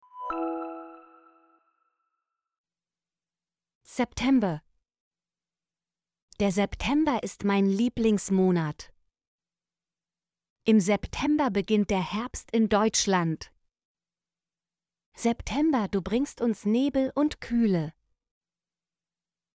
september - Example Sentences & Pronunciation, German Frequency List